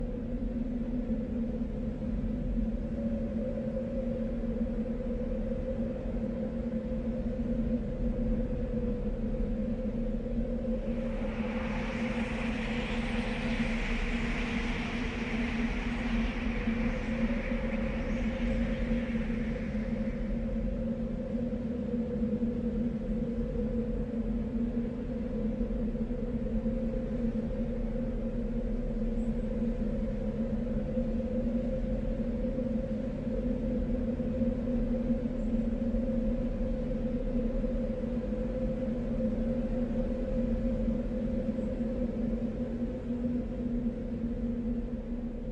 Звуки ветра в пустыне
Шум мощного ветра в пустыне